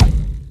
miss.ogg